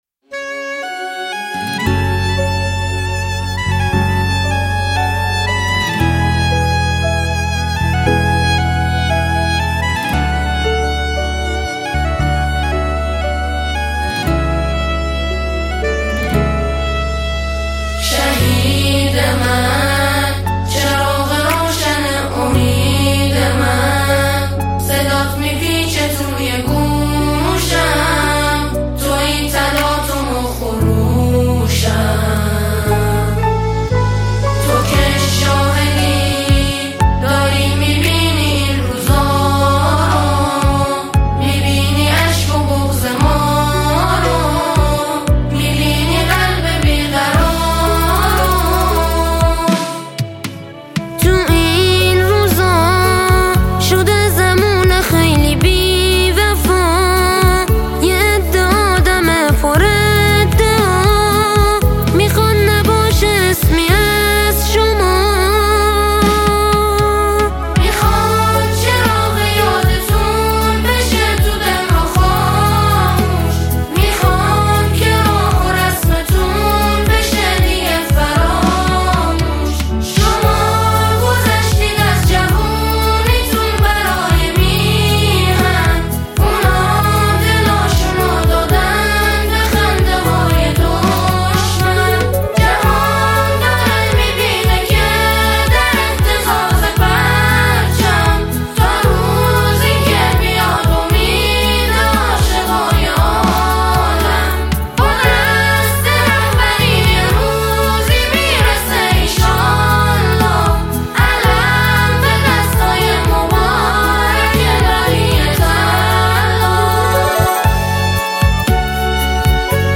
خطابیه‌ای است سوزناک و در عین حال کوبنده
ژانر: سرود ، سرود انقلابی ، سرود مناسبتی